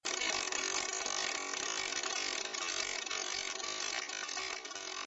dial.wav